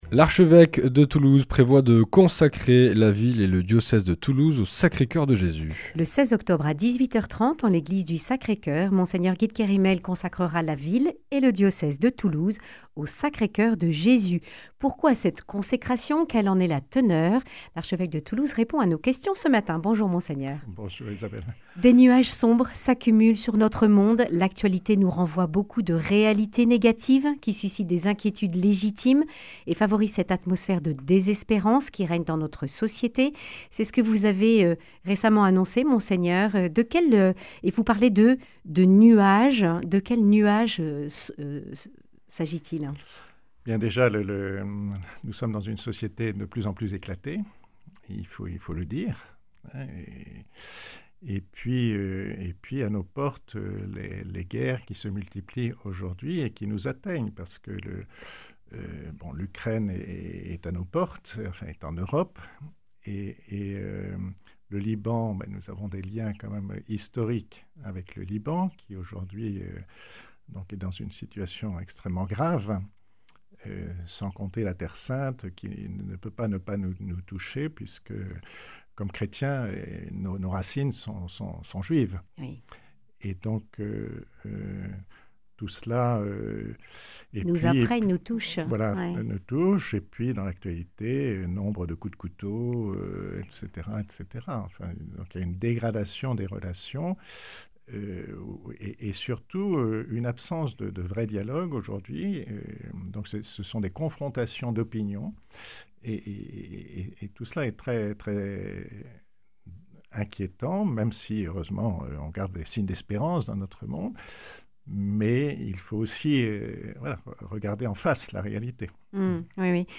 Monseigneur Guy de Kerimel, archevêque de Toulouse, présente l’acte de consécration au Sacré-Coeur de la ville et du diocèse de Toulouse. Une réponse spirituelle en ces temps troublés.
Le grand entretien